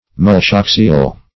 Search Result for " multiaxial" : The Collaborative International Dictionary of English v.0.48: Multiaxial \Mul`ti*ax"i*al\, a. [Multi- + axial.]